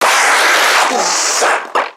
NPC_Creatures_Vocalisations_Infected [19].wav